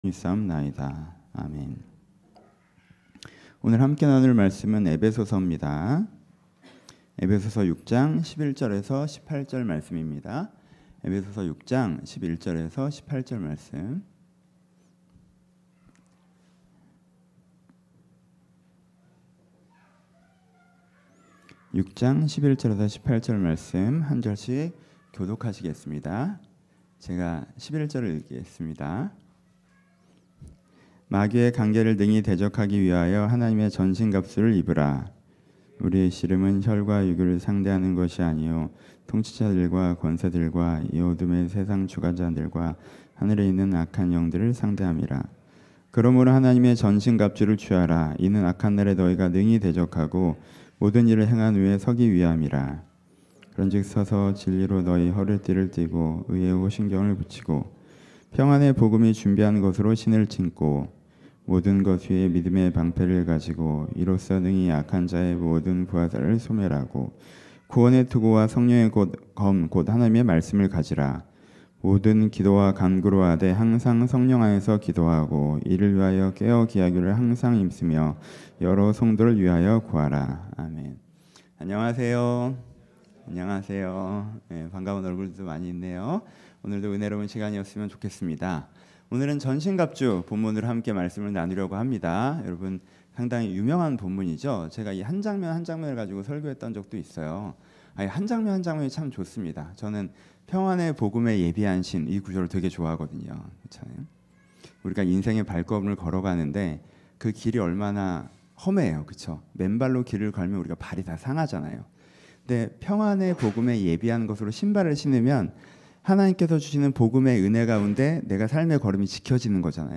설교 팟캐스트 podcast